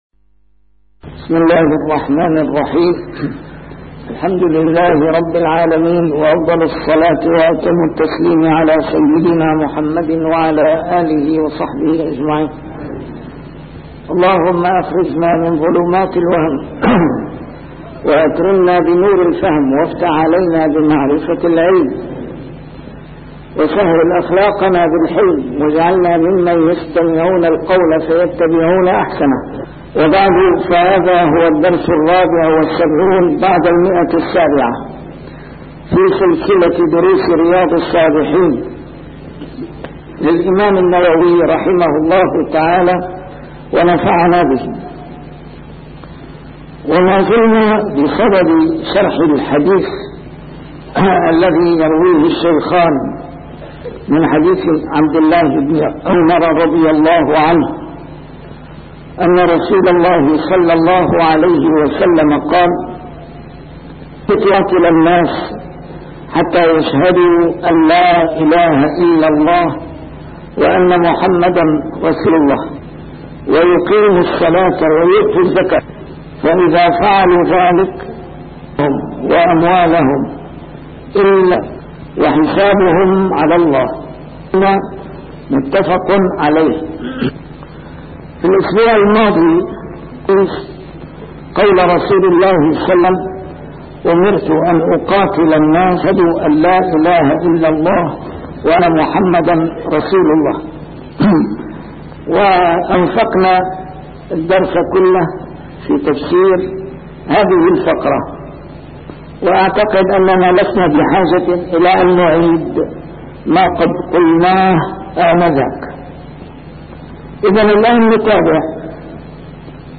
A MARTYR SCHOLAR: IMAM MUHAMMAD SAEED RAMADAN AL-BOUTI - الدروس العلمية - شرح كتاب رياض الصالحين - 774- شرح رياض الصالحين: المحافظة على الصلوات المكتوبات